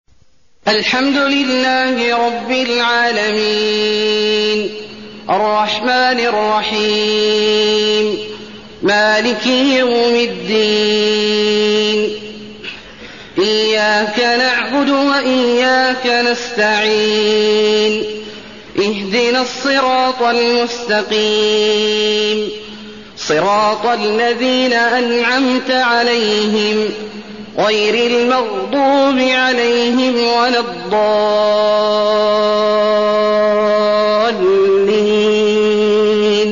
المكان: المسجد النبوي الفاتحة